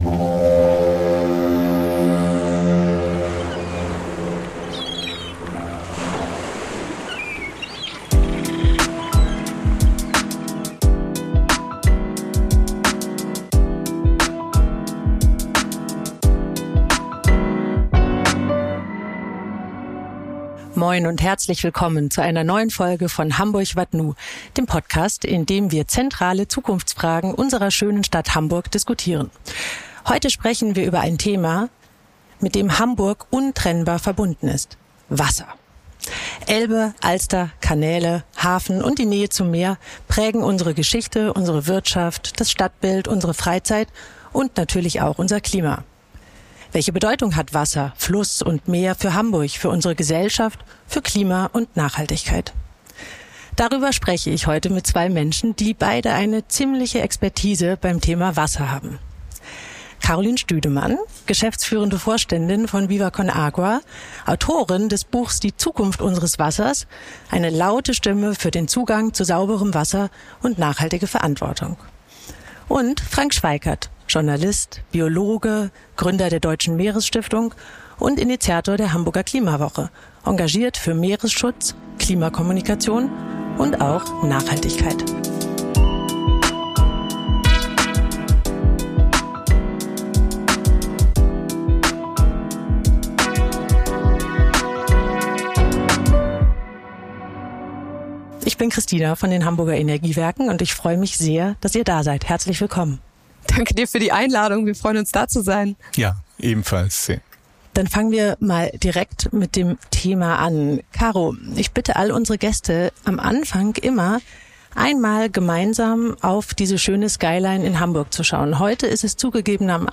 Ein Gespräch über Wertschätzung, globale Verantwortung und darüber, warum Wasserschutz weit mehr ist als ein Umweltthema. Aufgenommen in Wilhelmsburg mit Blick auf unsere Wasserstadt Hamburg.